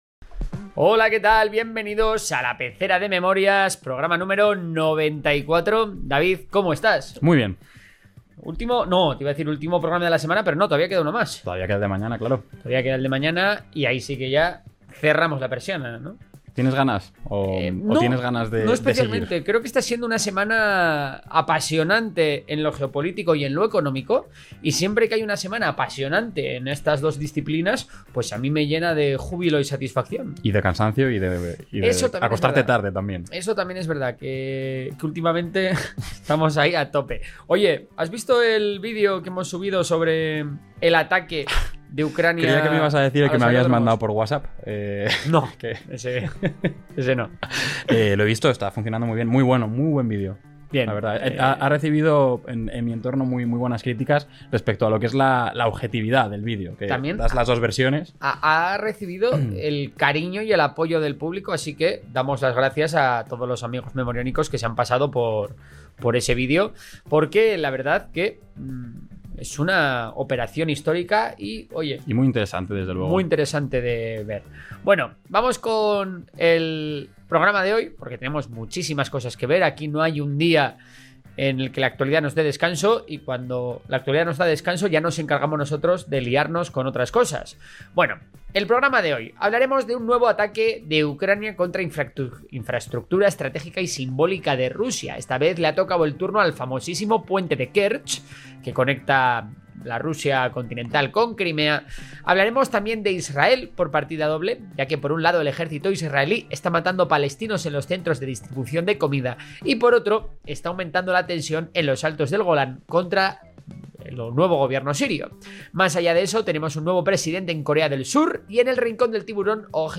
Relato de guerra